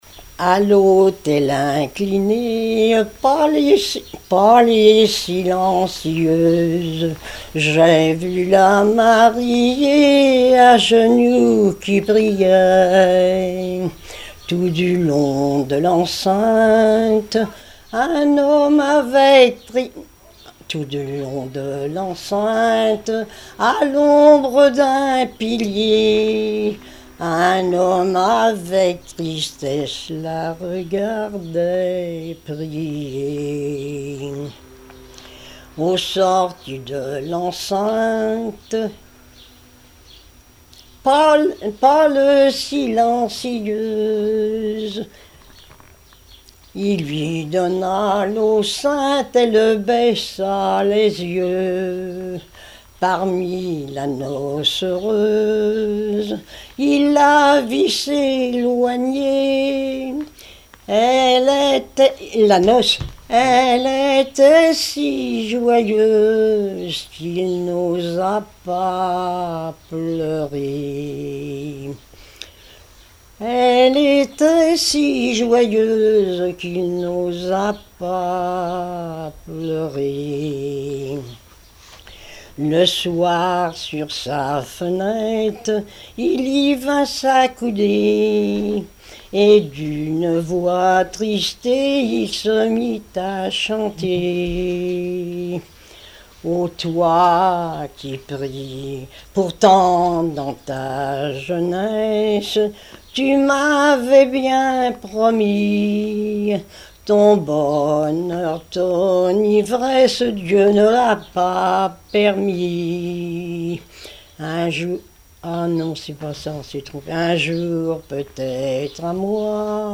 Genre strophique
témoigneges et chansons populaires
Pièce musicale inédite